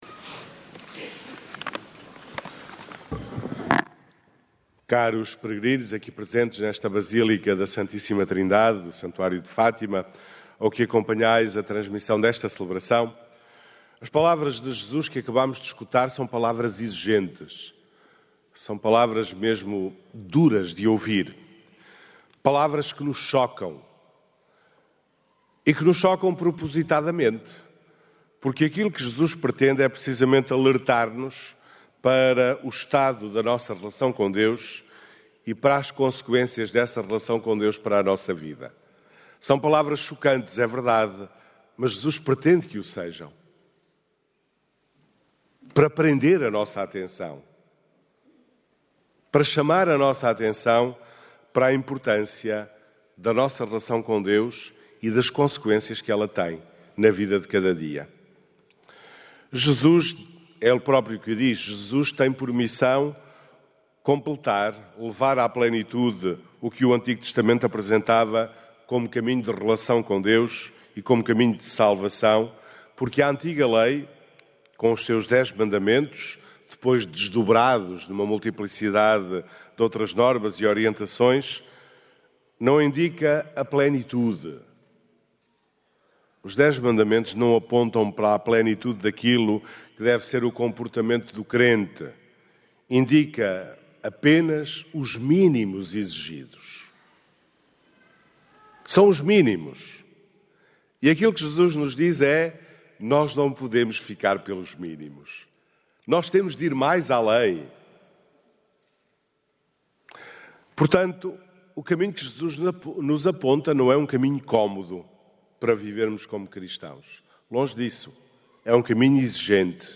Na missa deste domingo, a assembleia reunida na Basílica da Santíssima Trindade foi desafiada a renovar o coração e a relação com Deus mediante uma reconciliação ativa e fraterna.